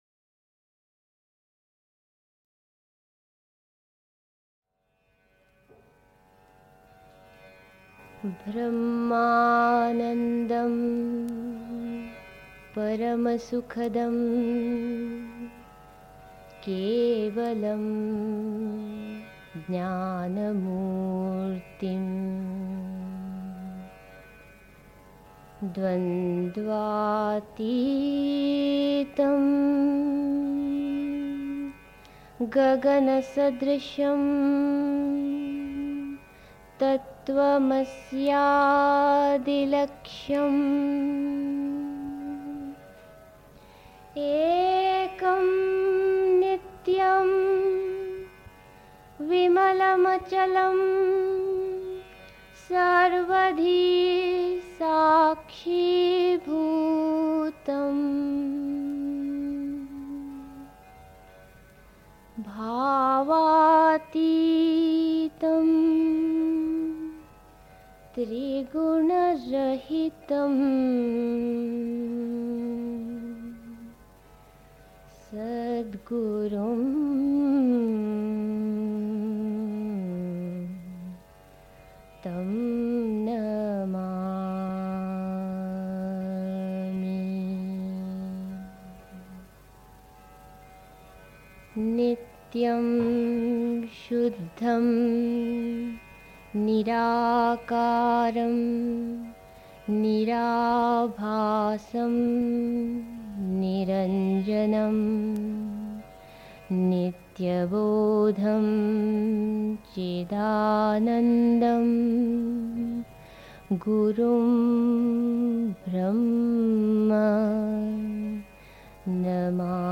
1. Einstimmung mit Musik. 2. Zwei Pfade bieten sich den Schritten des Yogin (Sri Aurobindo, CWSA Vol. 12, p. 96) 3. Zwölf Minuten Stille.